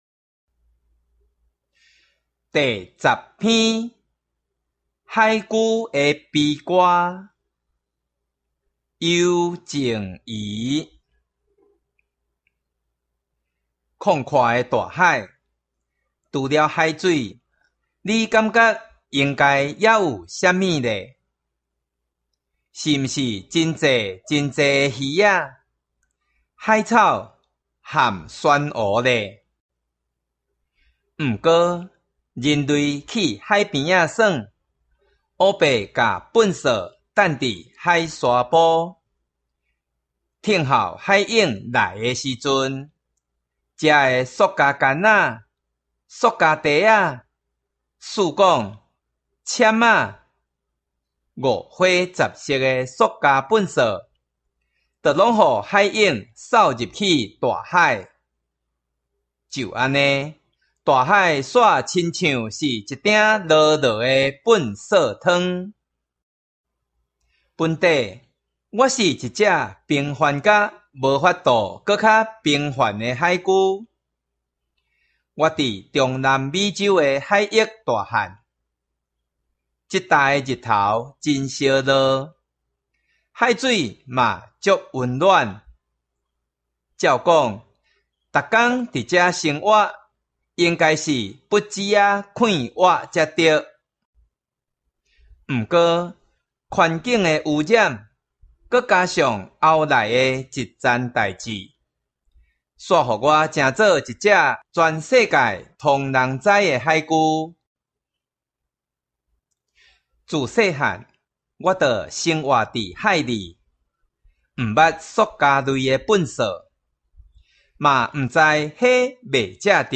閩南語朗讀